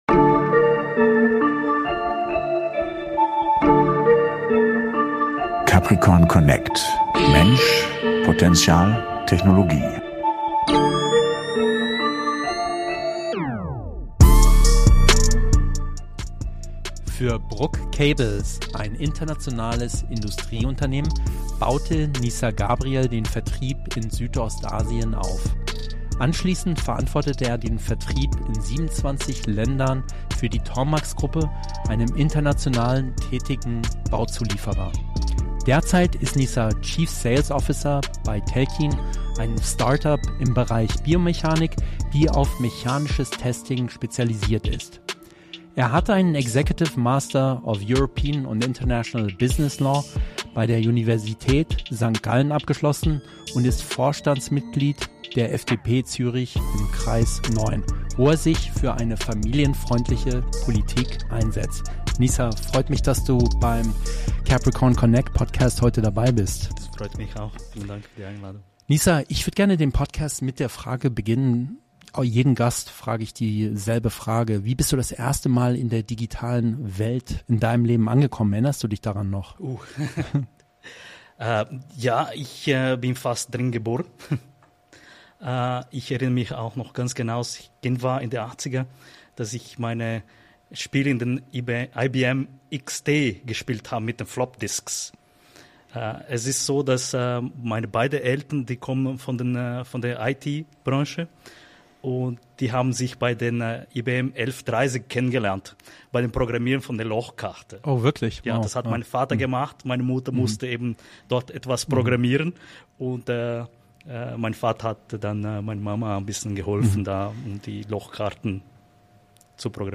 #45- Interview